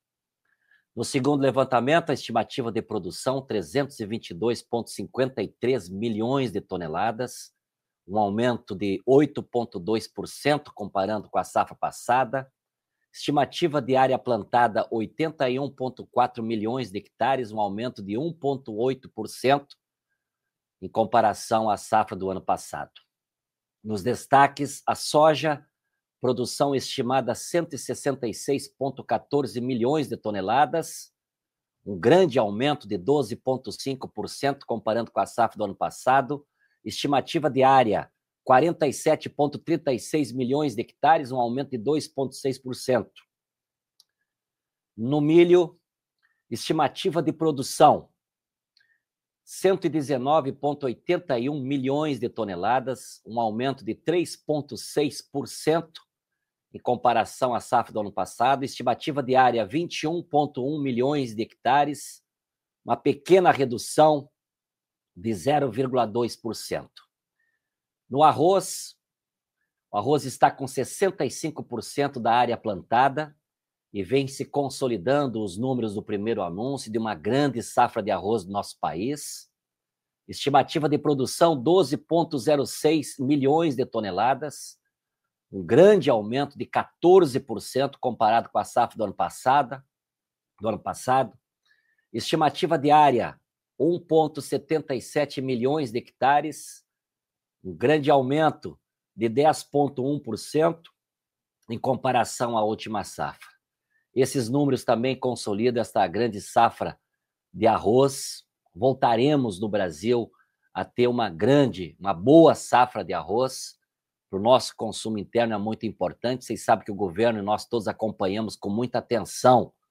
Edegar Pretto comenta os números da safra
Edegar-Pretto-comenta-os-numeros-da-safra-nacional-de-graos.mp3